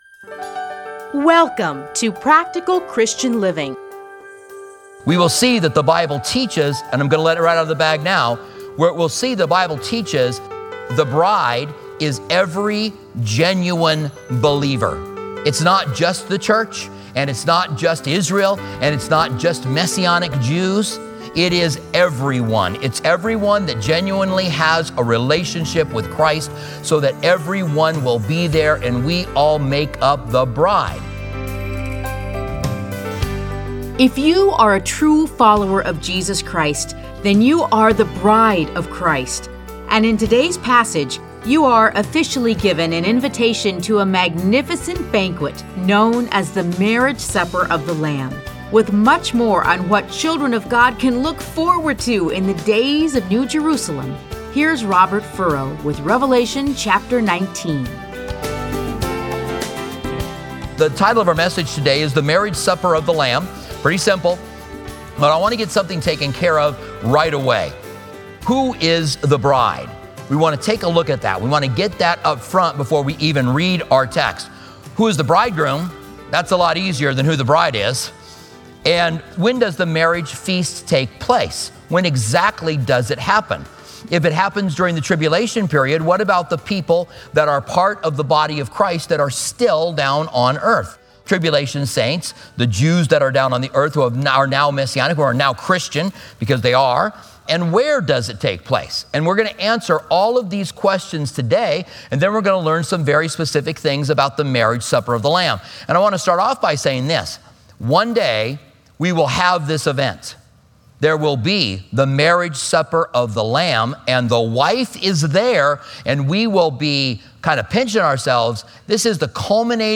Listen to a teaching from Revelation 19:1-10.